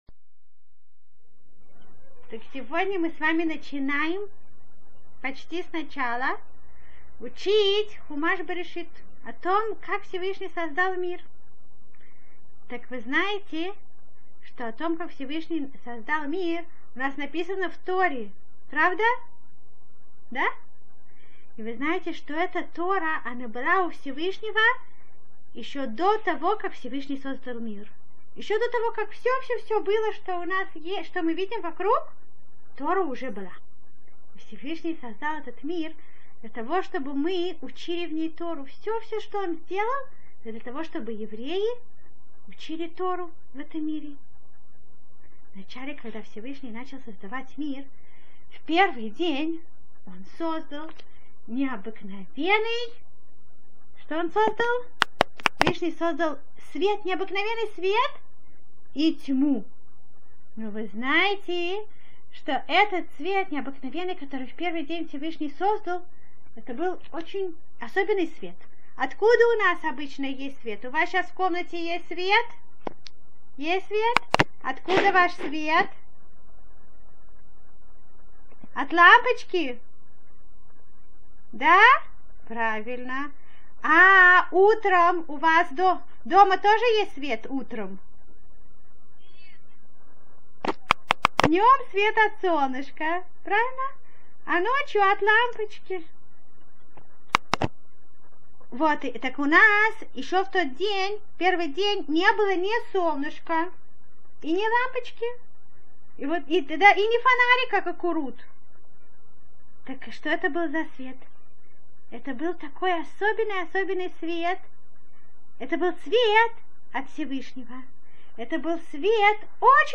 Урок 1 – Шесть дней Творения.